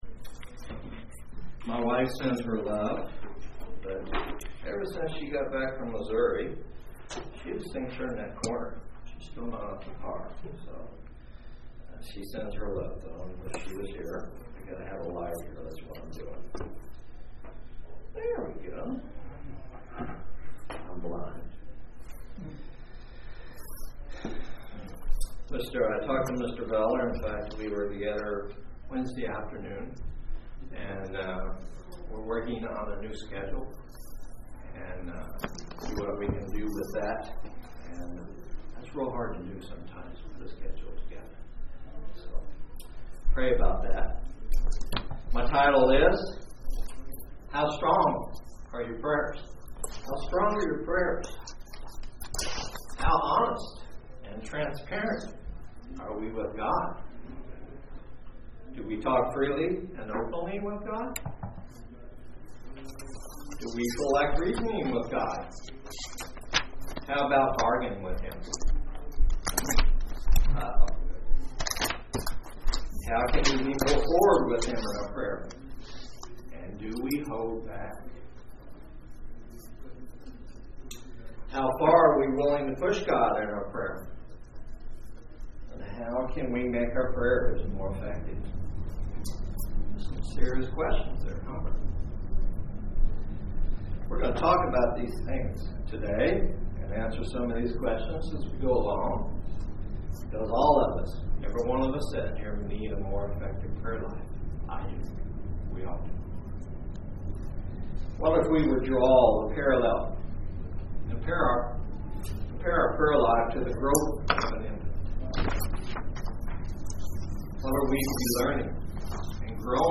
This sermon gives specific points to strengthen your prayers.